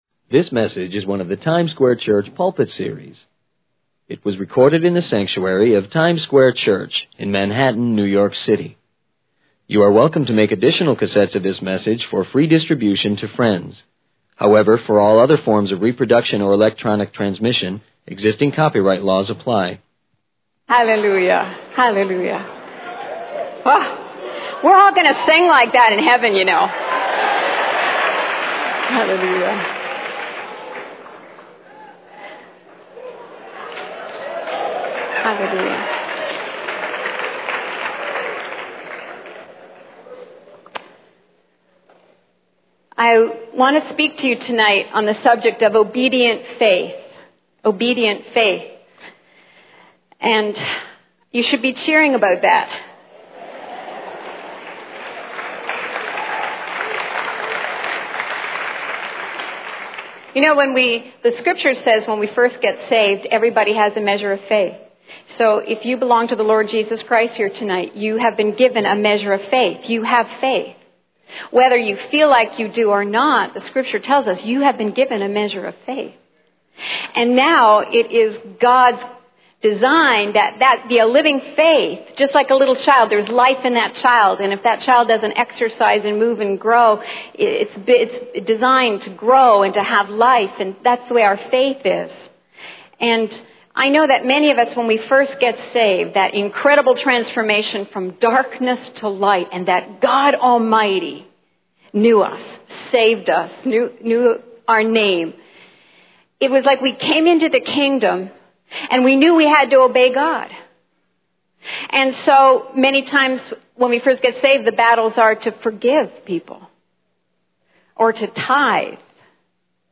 In this sermon, the speaker discusses the importance of obedient faith in the lives of believers. He emphasizes that every person who belongs to Jesus Christ has been given a measure of faith.
Full Transcript This message is one of the Times Square Church Pulpit Series. It was recorded in the sanctuary of Times Square Church in Manhattan, New York City.